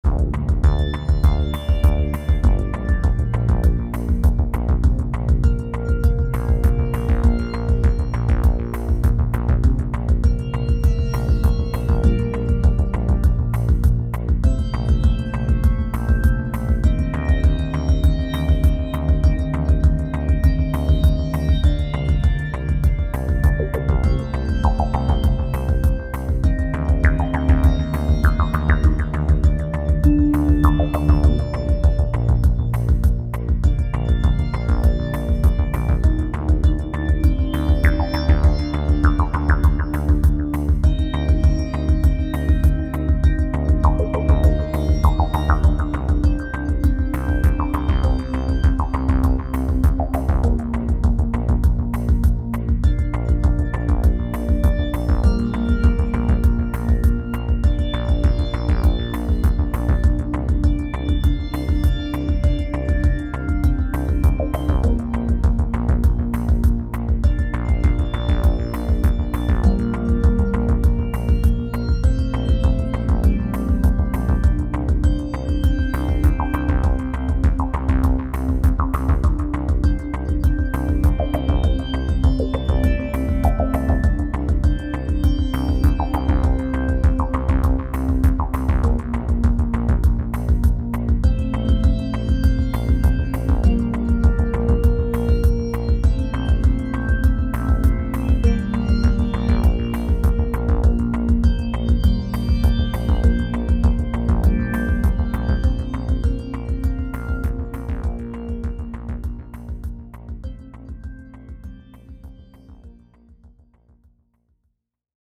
Voici 3 ambiances musicales pour illustrer les capacités de la Société Henon.